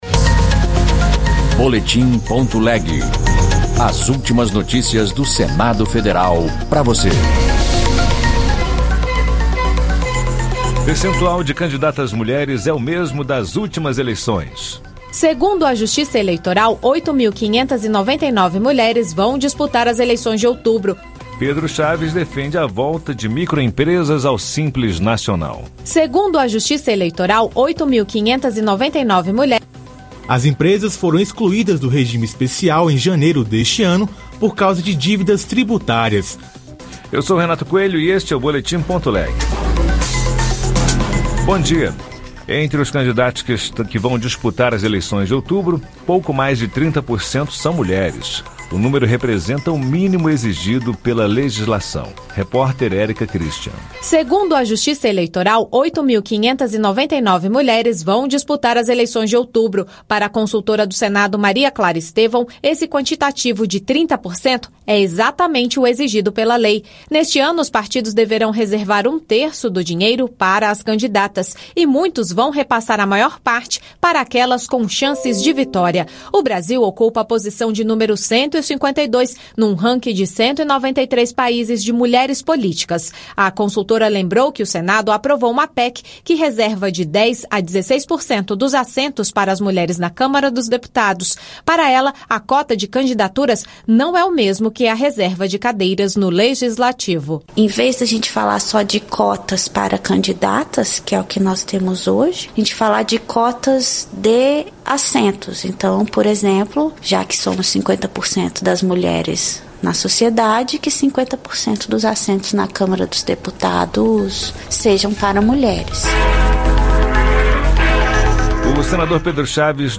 Notícias do Senado Federal em duas edições diárias